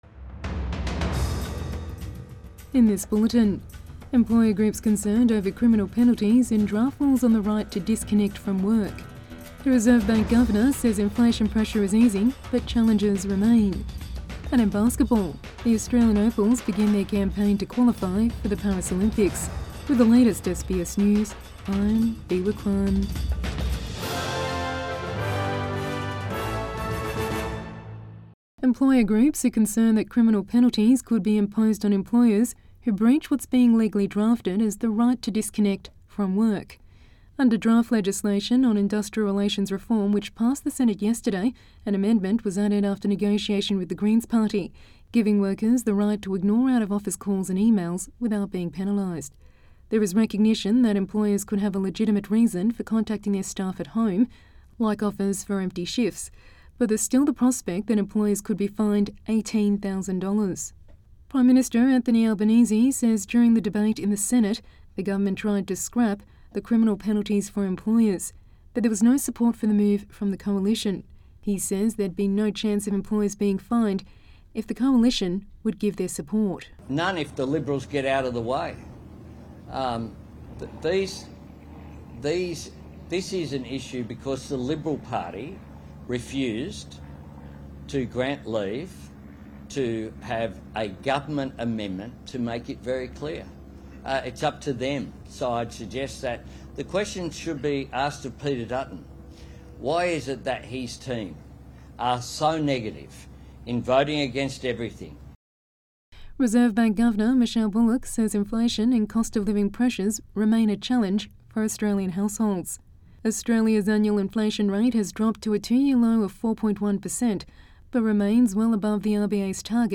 Midday News Bulletin 9 February 2024